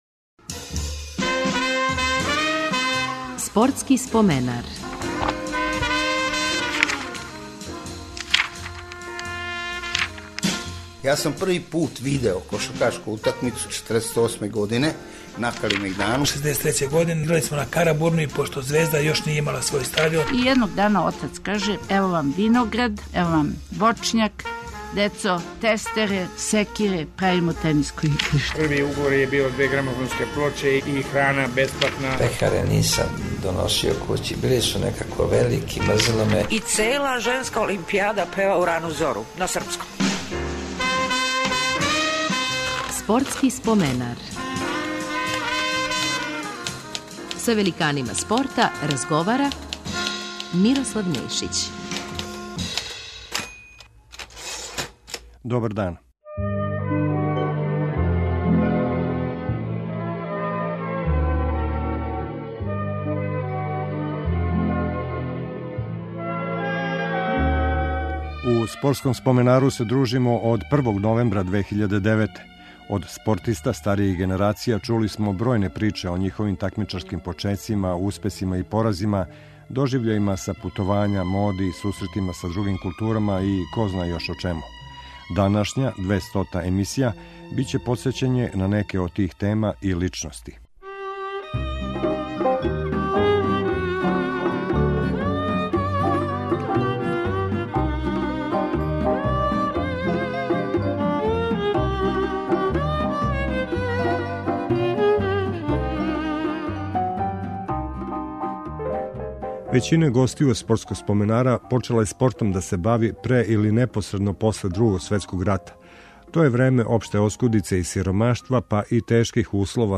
Коришћењем инсерата из преноса и сећања актера подсетићемо на неке од значајних догађаја из наше спортске прошлости: освајања сребрне олимпијске медаље маратонца Фрање Михалића, тријумфа фудбалера над Енглеском 5:0.